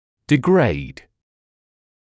[dɪ’greɪd][ди’грэйд]уменьшать, снижать (напр. силу); деградировать, ухудшаться